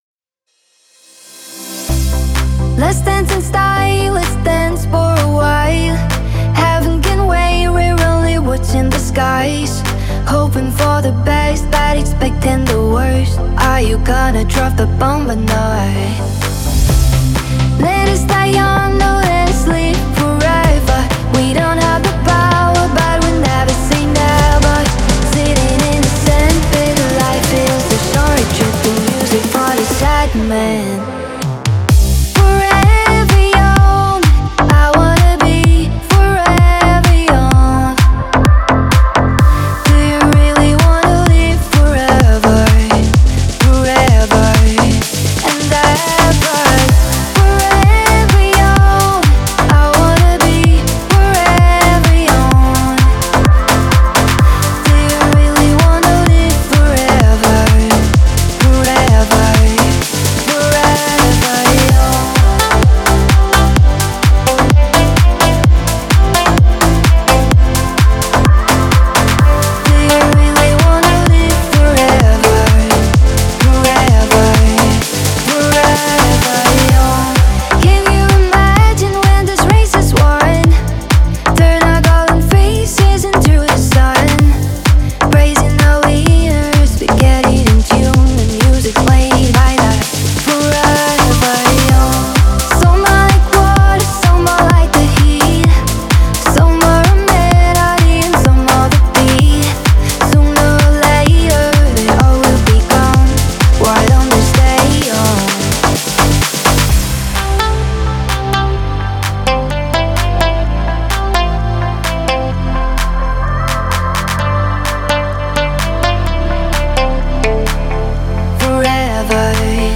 музыка для тренировки